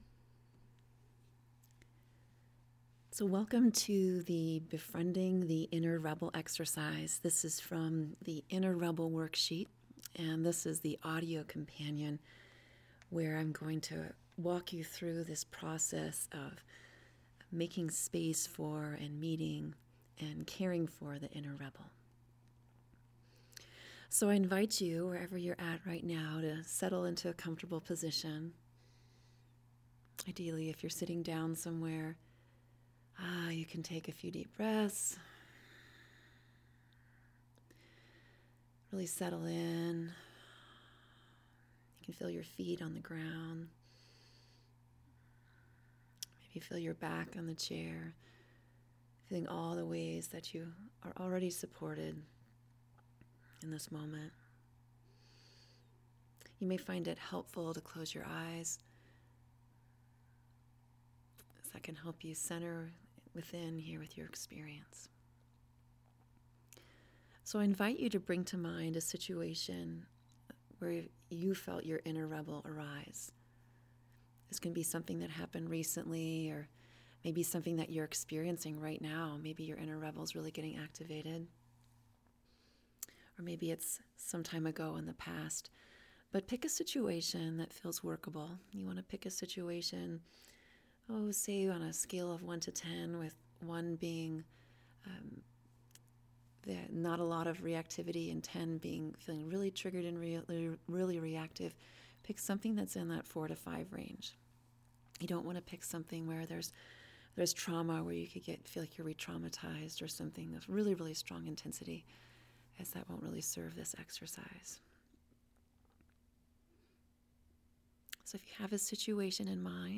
[Audio exercise and worksheet] - The Inner Rebel worksheet